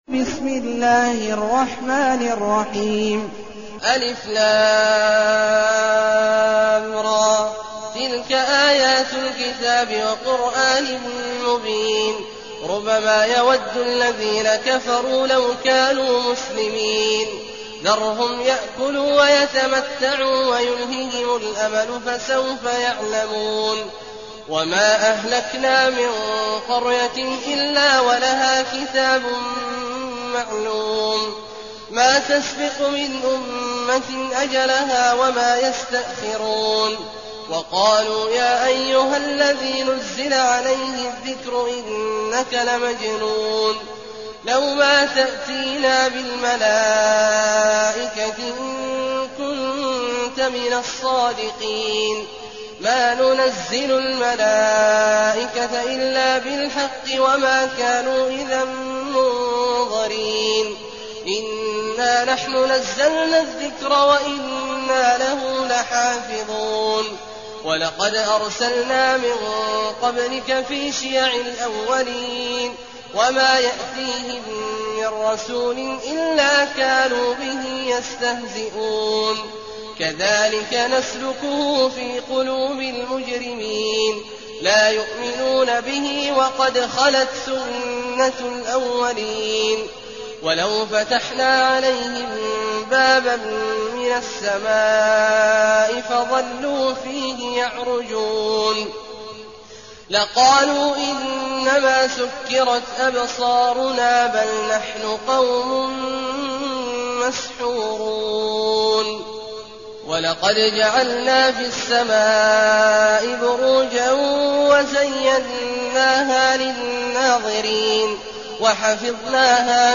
المكان: المسجد النبوي الشيخ: فضيلة الشيخ عبدالله الجهني فضيلة الشيخ عبدالله الجهني الحجر